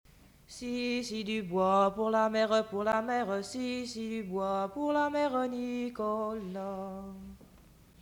Chanson
Emplacement Saint-Pierre